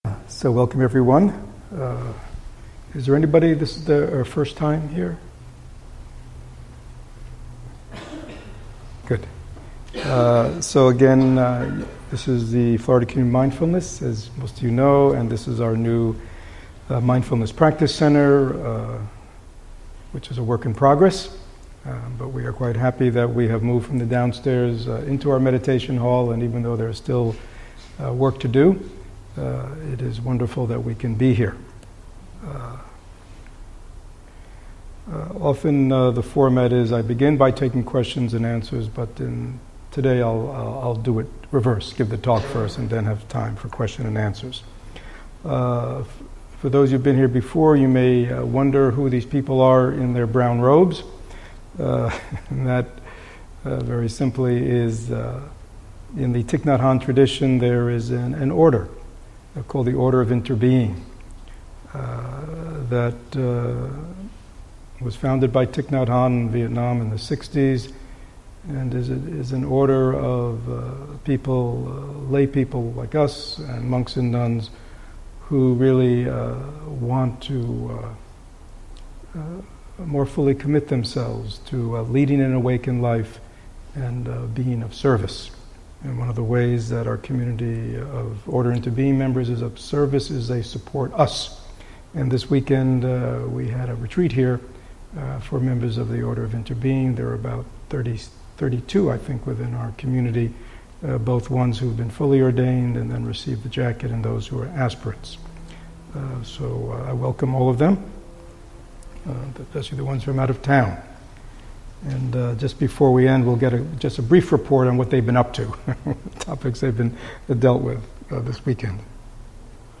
Sangha Talk